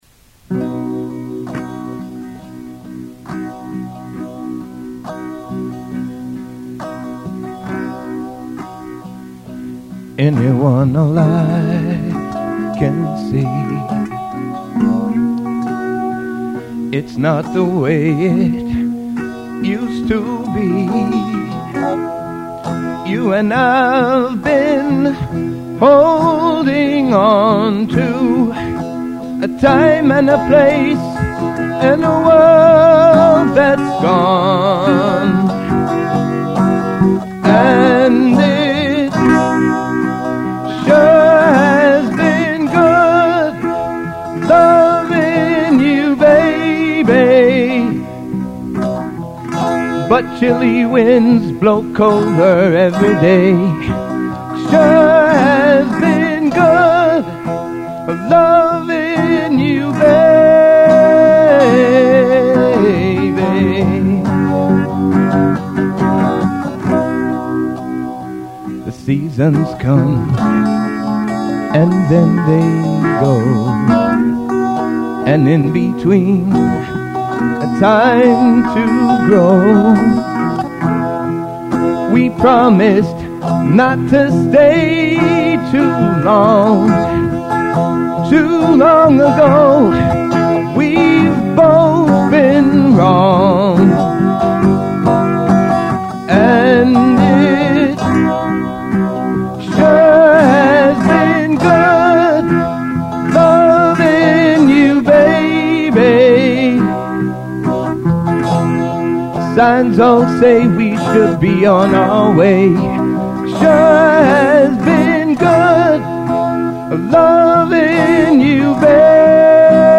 piano
state of the art eight track studio
a demo (the only recording anywhere)